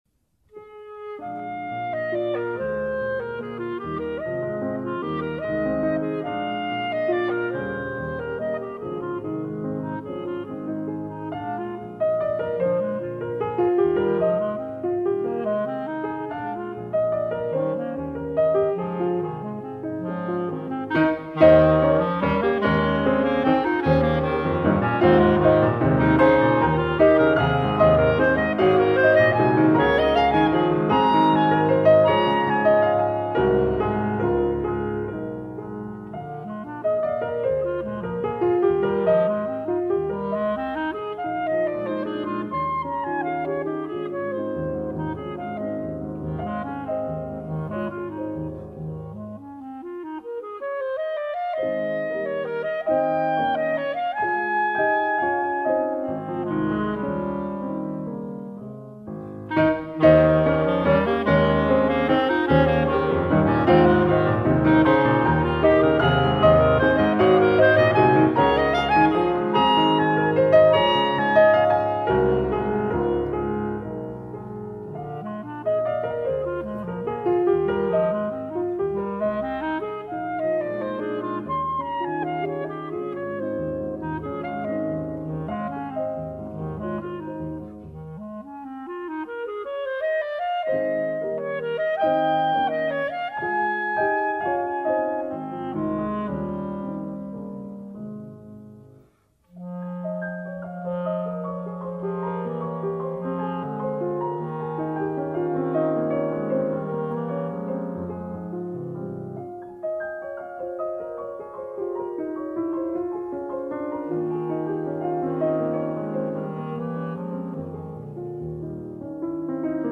clarinet
piano
cello
音樂類型：古典音樂
音樂到處充滿著各種幽暗的氣氛，像是黃昏時分的散步；主題之間的對比平和而且穩健。